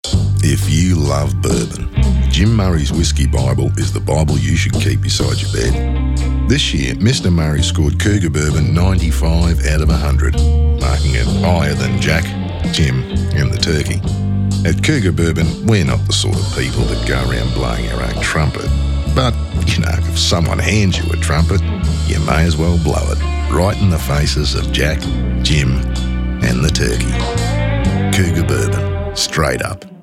One of the radio spots celebrates the brand’s 95/100 score on whiskey guide Jim Murray’s Whisky Bible.